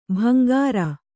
♪ bhangāra